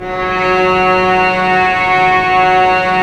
Index of /90_sSampleCDs/Roland L-CD702/VOL-1/STR_Vcs Bow FX/STR_Vcs Sul Pont